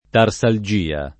tarsalgia [ tar S al J& a ] s. f. (med.)